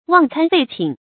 忘餐廢寢 注音： ㄨㄤˋ ㄘㄢ ㄈㄟˋ ㄑㄧㄣˇ 讀音讀法： 意思解釋： 見「忘寢廢食」。